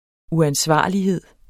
Udtale [ uanˈsvɑˀliˌheðˀ ]